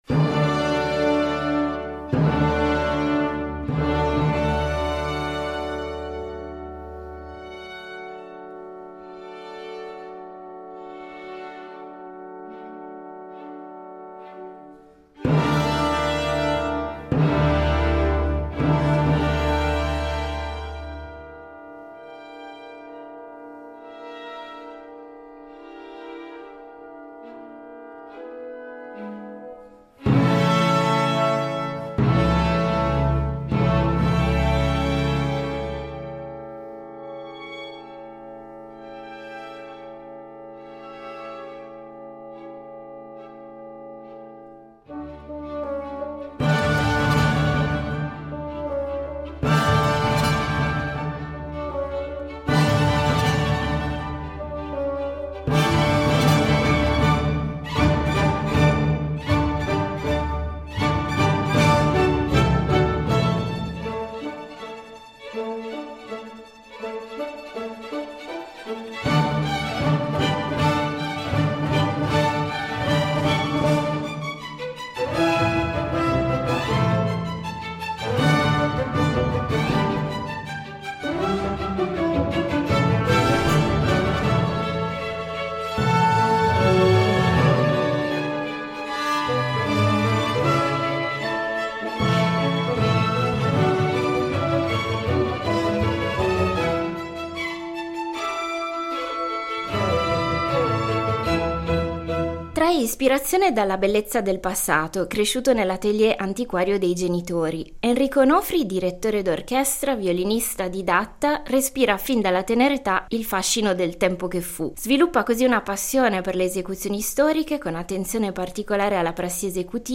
Incontro con Enrico Onofri